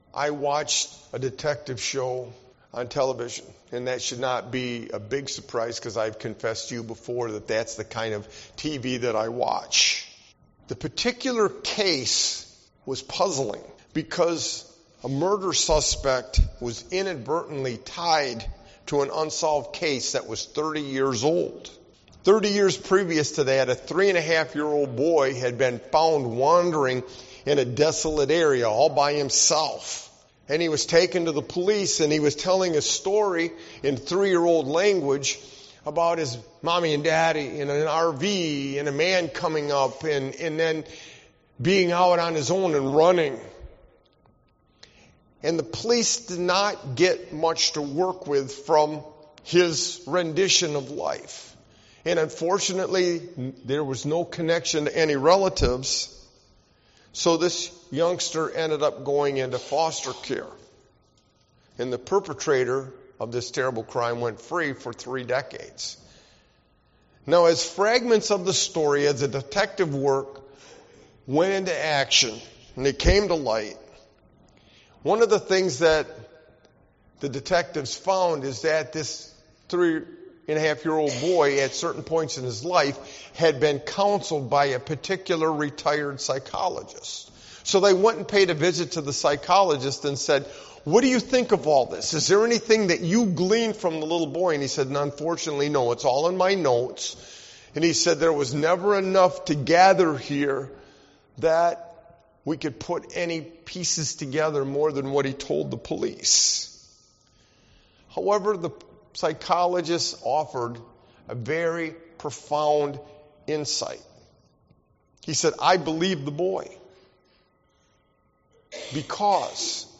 Sermon-Why-a-Sovereign-God-is-crucial-XV-52922.mp3